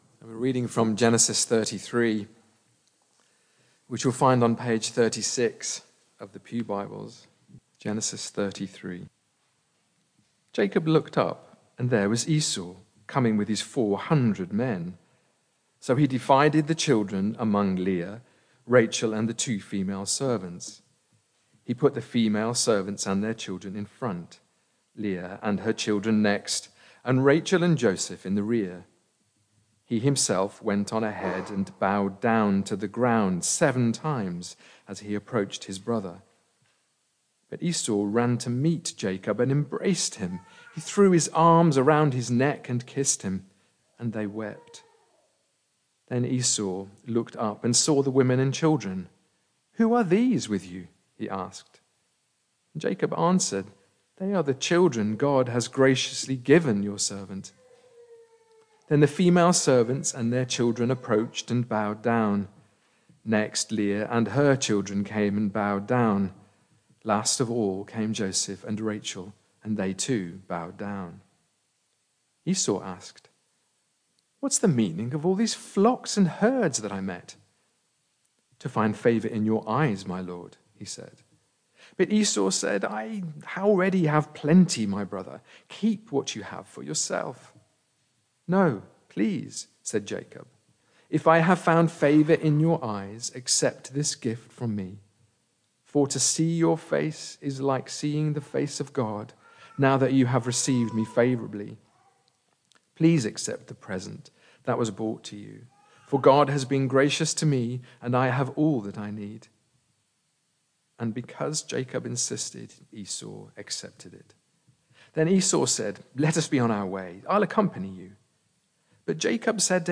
Media for Barkham Morning Service on Sun 06th Jul 2025 10:00
Reading and Sermon